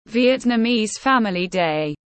Ngày gia đình Việt Nam tiếng anh gọi là Vietnamese Family Day, phiên âm tiếng anh đọc là /ˌvjɛtnəˈmiːz ˈfæmɪli deɪ/